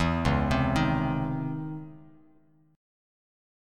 C#mM9 chord